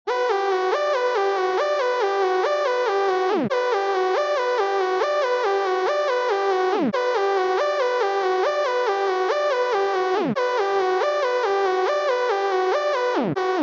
ORBIT_RTB_140_lead_synth_loop_chainsaw_Emin